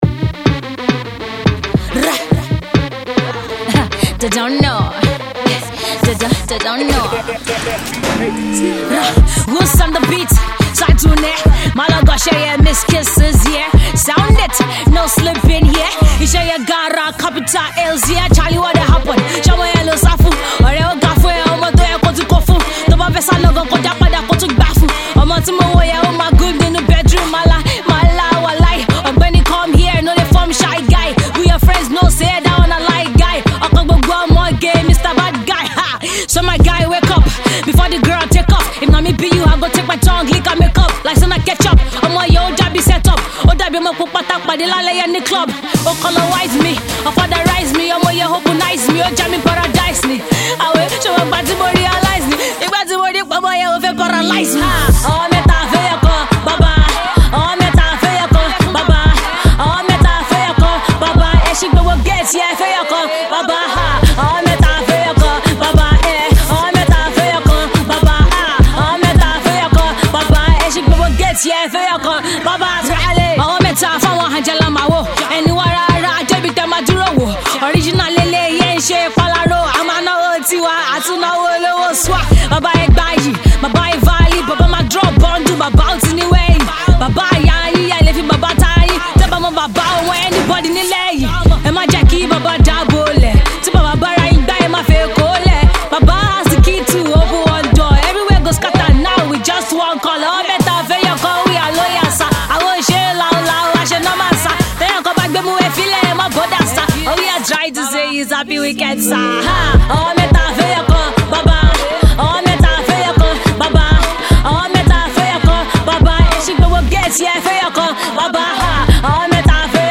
a street rap Anthem